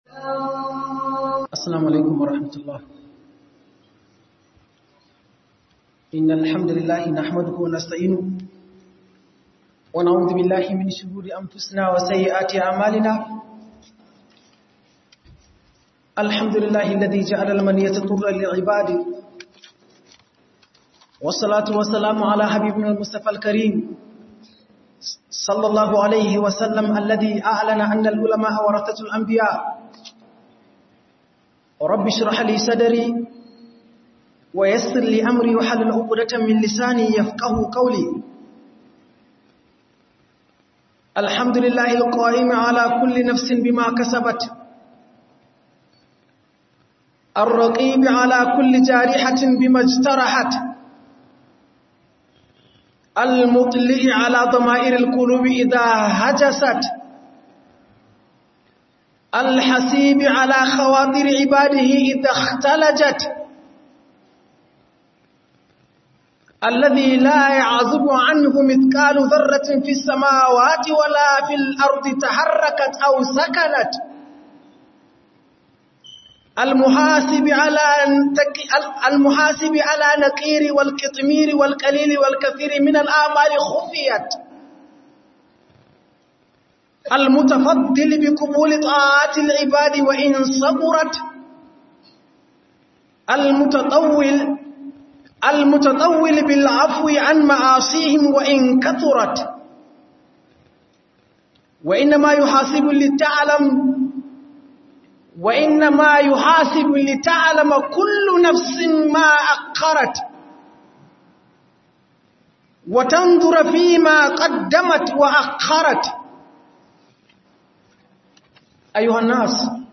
Gyara Kayanka - HUDUBA